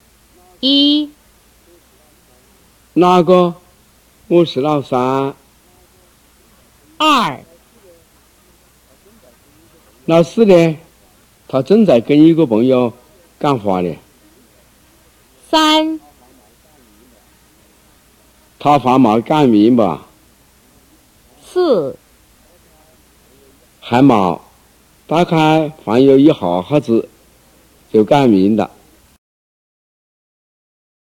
In each of the audio files below, the speaker will say the following, at least how they would say the same thing in their dialect.
4. Chángshā Dialect (Xiang Group; Hunan Province)
04-changsha-hua.m4a